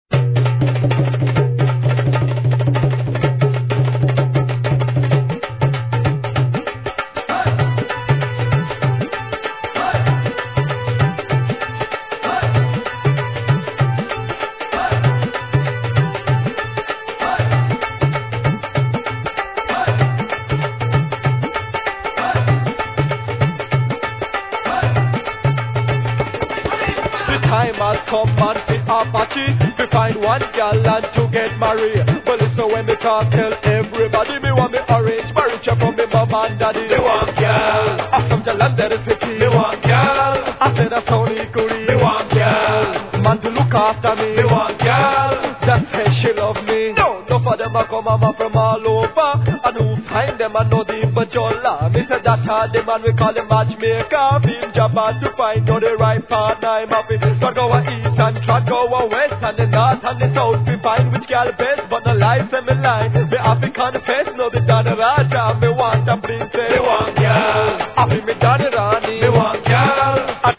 1. REGGAE